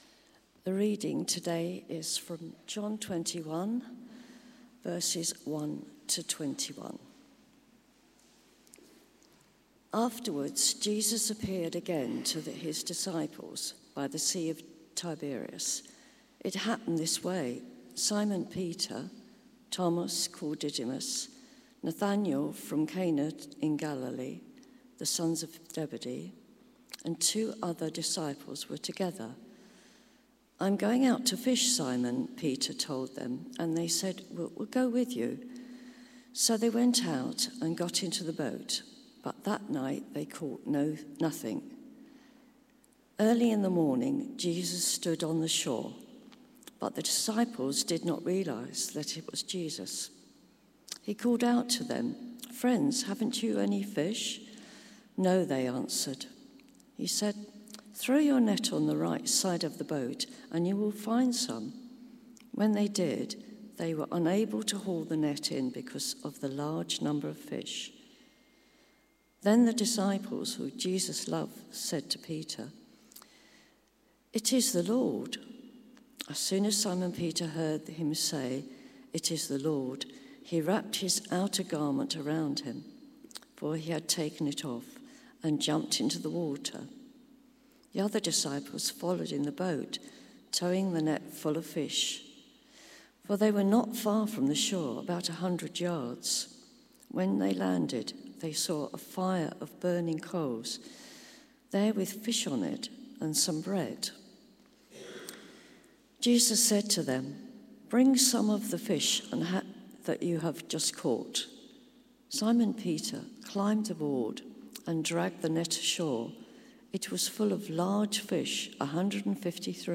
The Recommissioning of Peter Sermon